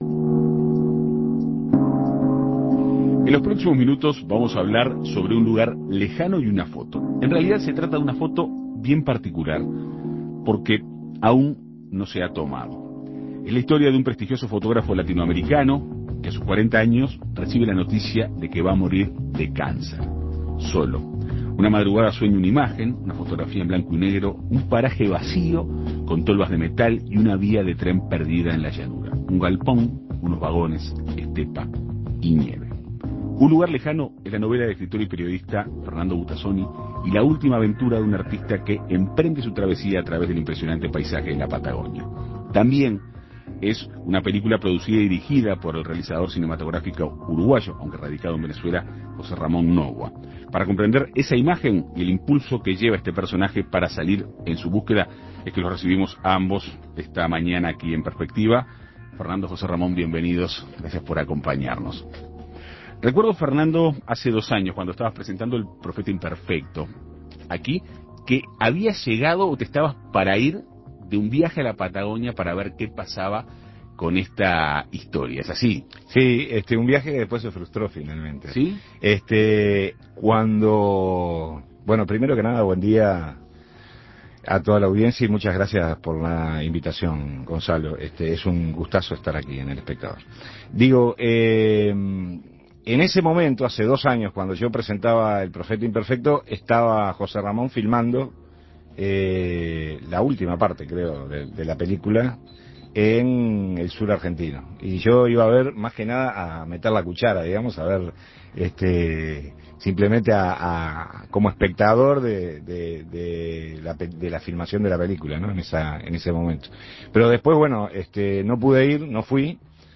La historia llegará próximamente al cine bajo la producción y dirección del realizador cinematográfico uruguayo José Ramón Novoa. Para comprender esa imagen y el impulso que lleva a este personaje a salir en su búsqueda, En Perspectiva Segunda Mañana dialogó con el director y con el escritor de la obra.